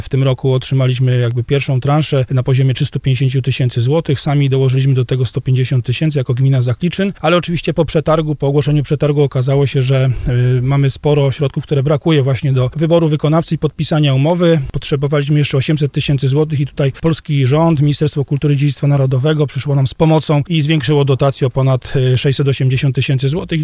Jak mówił w programie Słowo za Słowo burmistrz Zakliczyna Dawid Chrobak, zwiększenie dofinansowania umożliwi konstrukcyjne domknięcie i zabezpieczenie tego zabytkowego obiektu.